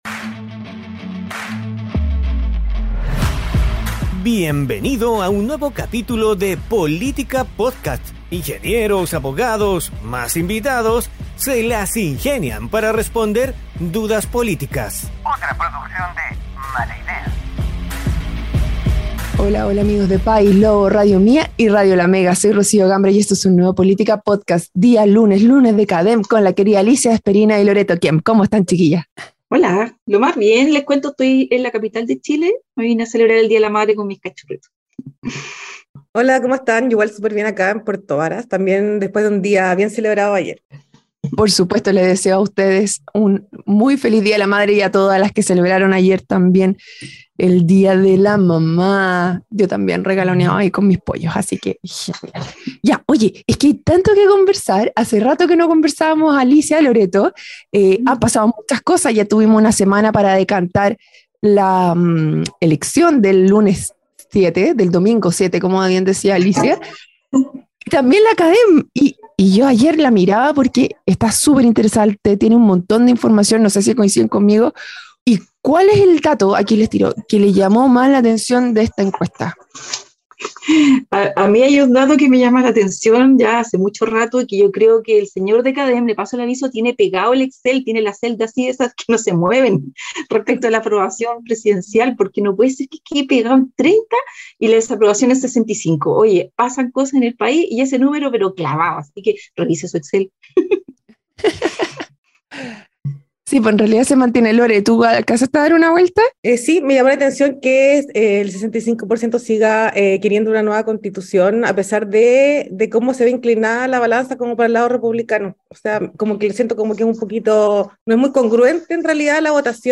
programa donde junto a panelistas estables e invitados tratan de responder dudas políticas.